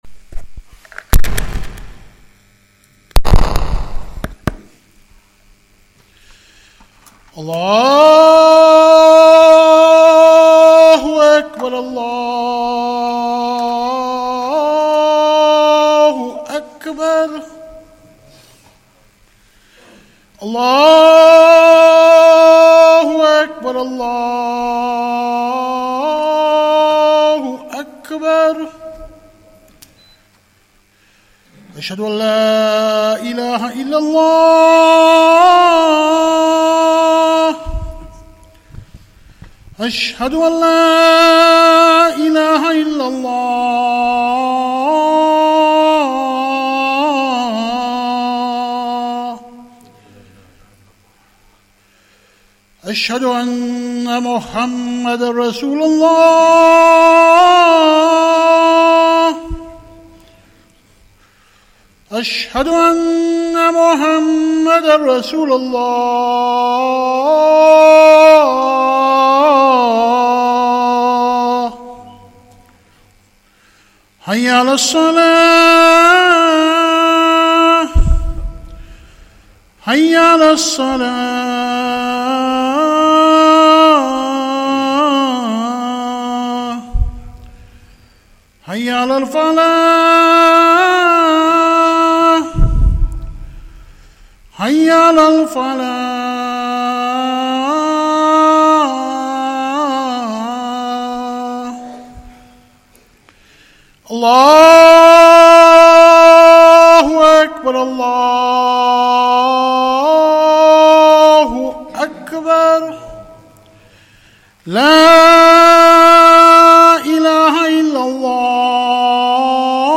Masjid Al Farouq | Jumuah